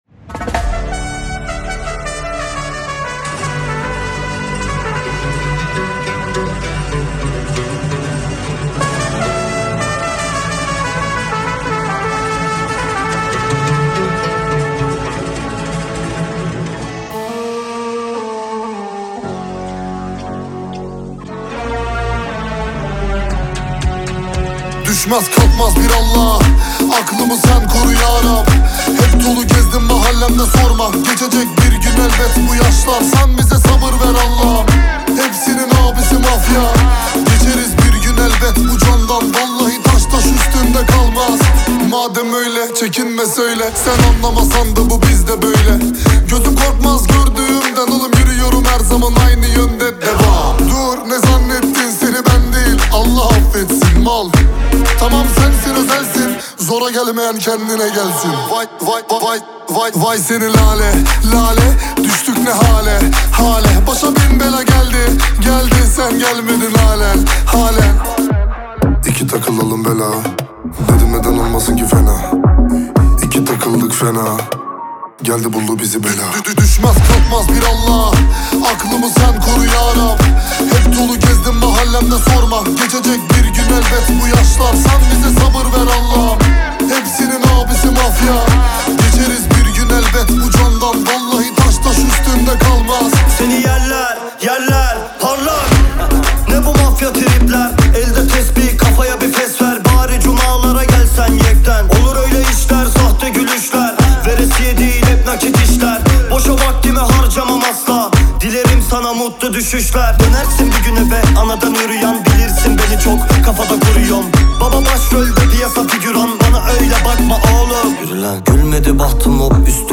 موزیک ترکی